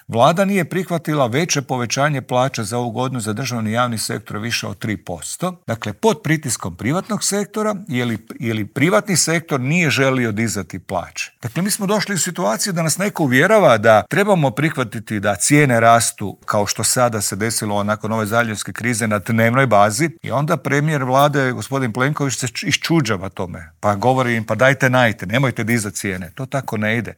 Intervjuu tjedna Media servisa